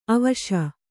♪ avaśa